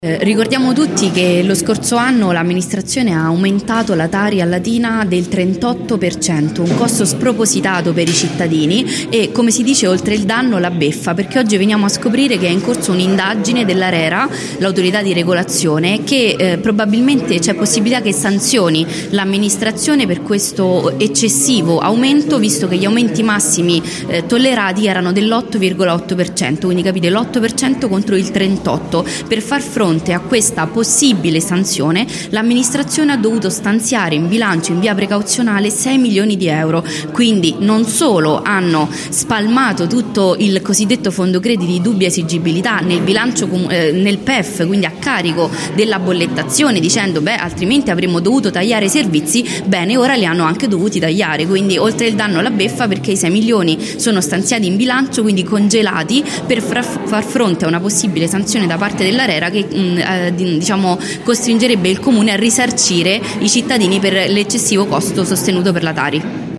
In una conferenza stampa che si è tenuta questa mattina nella sede di via Cattaneo, la capogruppo del movimento 5 stelle Maria Grazia Ciolfi,  quella del Pd Valeria Campagna, il capogruppo di Lbc Dario Bellini e il capogruppo di Latina per 2032 Nazareno Ranaldi hanno affrontato la questione che ha portato da due anni a questa parte a puntuali scontri in consiglio comunale.